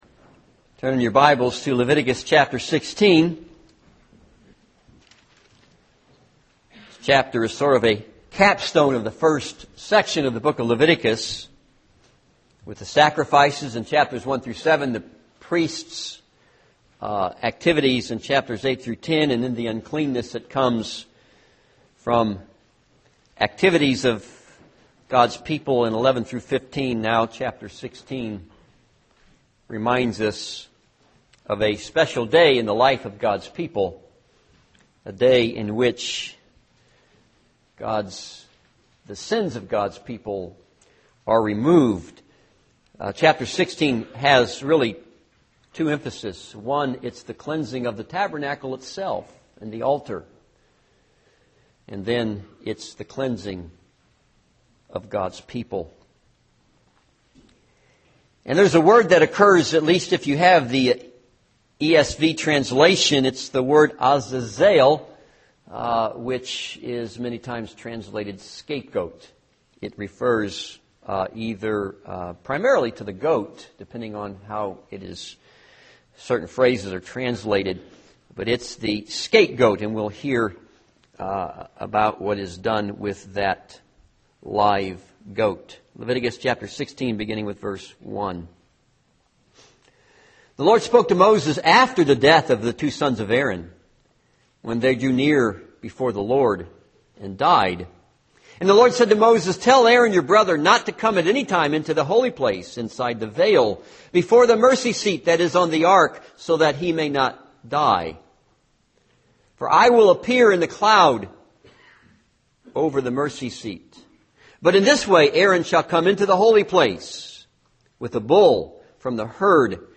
This is a sermon on Leviticus 16.